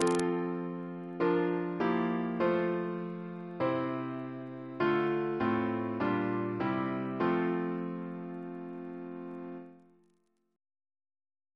Single chant in F Composer: Edward F. Rimbault (1816-1876) Reference psalters: OCB: 15